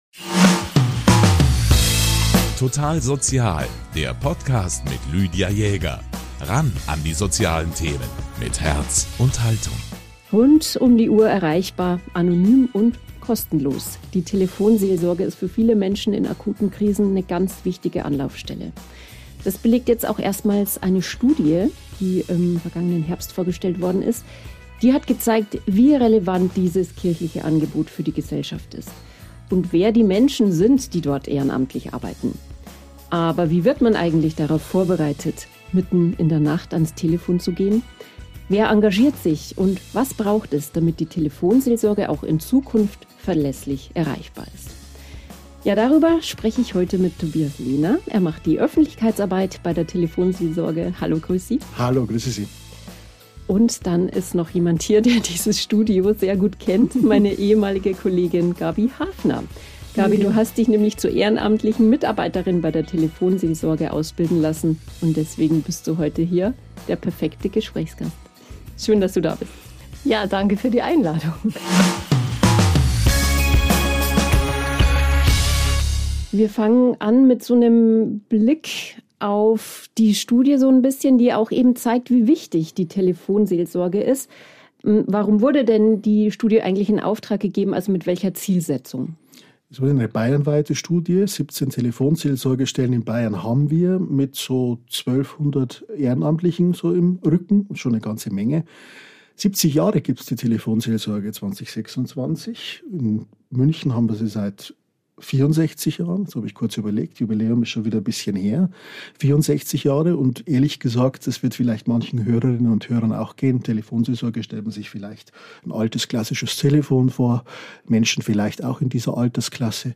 Wir sprechen darüber, wie die Ausbildung zur Telefonseelsorgerin oder zum Telefonseelsorger abläuft und erfahren, welche Themen die Anrufenden beschäftigen und warum oft schon ein offenes Ohr helfen kann. Eine Ehrenamtliche berichtet von ihren Erfahrungen am Telefon, von herausfordernden Gesprächen, aber auch davon, wie sinnstiftend die Arbeit ist und wie stark der Zusammenhalt im Team. Gleichzeitig wird deutlich, vor welchen Herausforderungen die Telefonseelsorge künftig steht – von Finanzierung bis zur Weiterentwicklung digitaler Beratungsangebote.